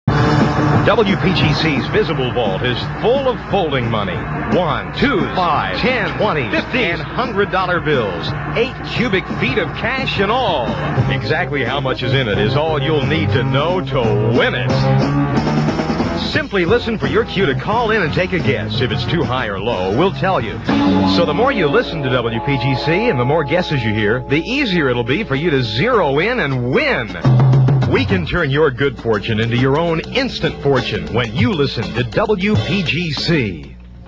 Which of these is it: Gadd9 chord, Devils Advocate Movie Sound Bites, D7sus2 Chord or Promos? Promos